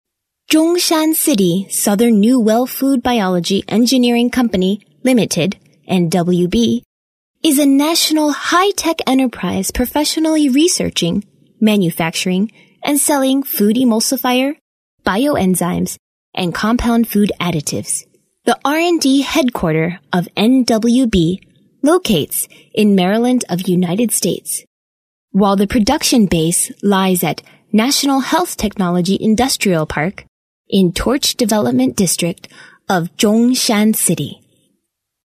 美式英语配音